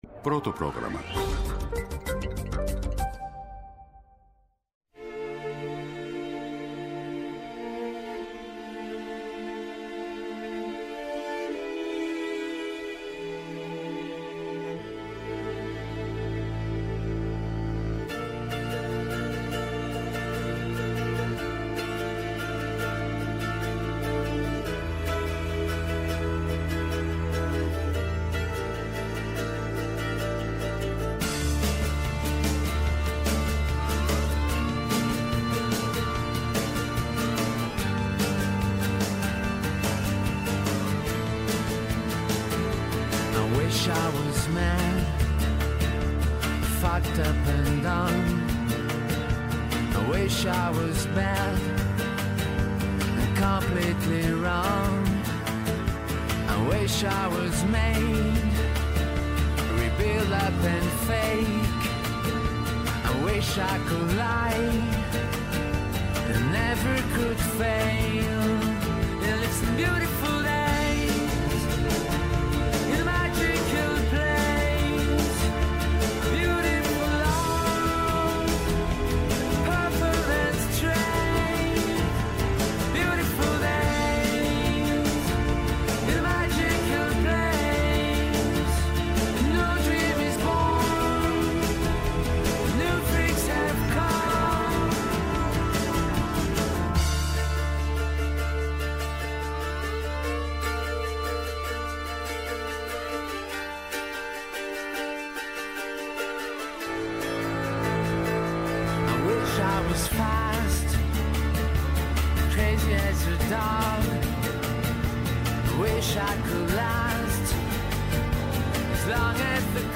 Ο Γρηγόρης Κωνσταντέλλος, δήμαρχος Βάρης-Βούλας-Βουλιαγμένης, αντιπρόεδρος της Κεντρικής Ένωσης Δήμων Ελλάδος (ΚΕΔΕ).